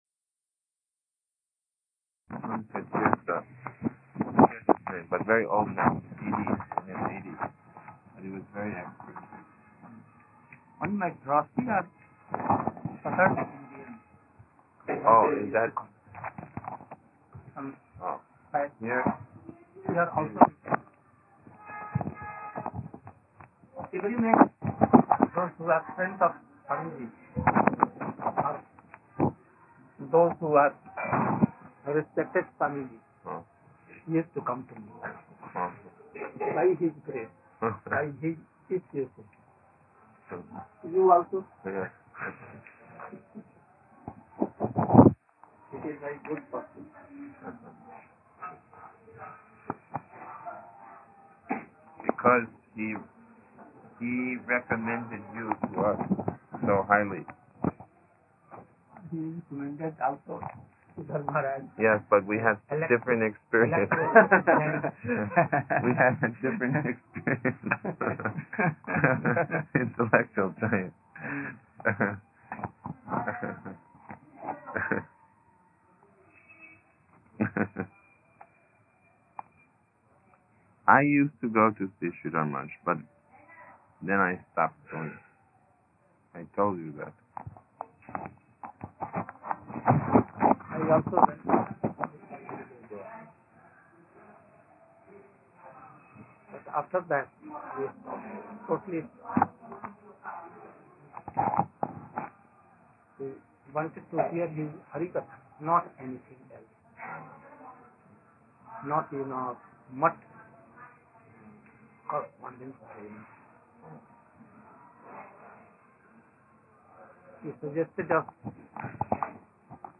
Darsana